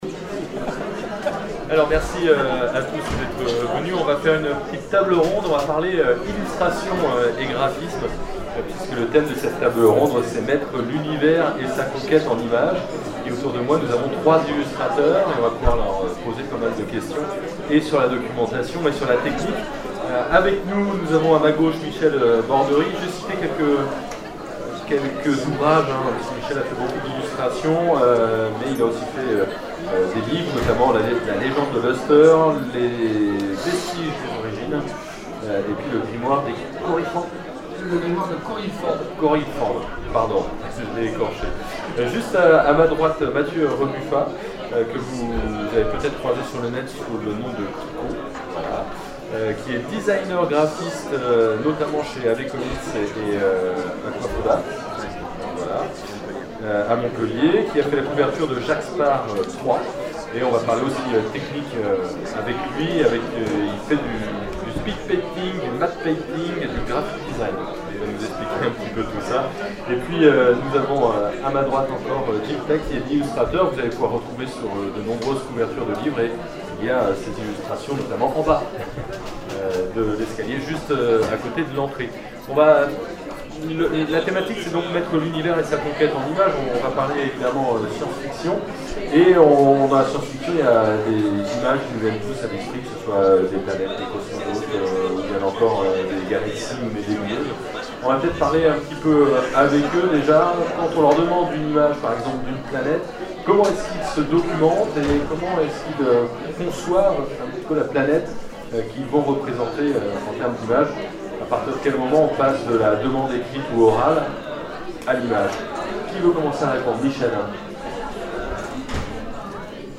Zone Franche 2012 : Conférence Mettre l'univers et sa conquête en images